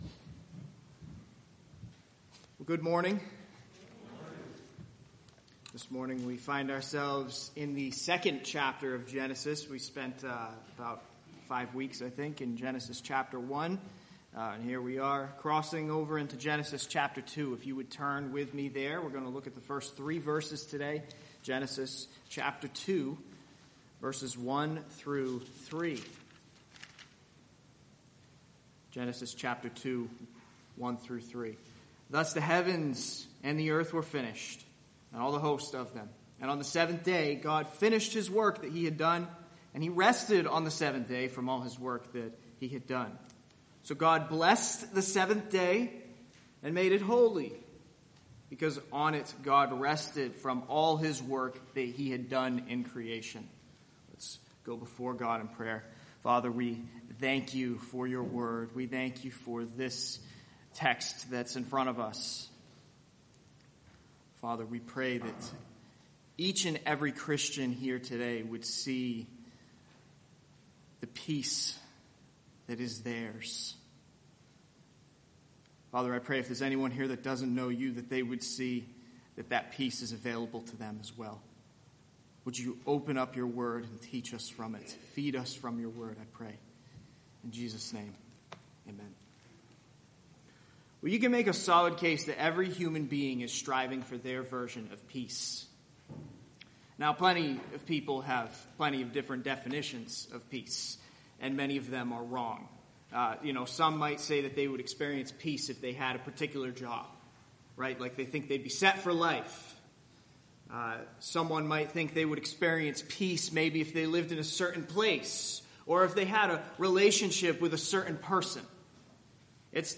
Sermons preached at West Bridgewater Community Church in West Bridgewater, Massachusetts.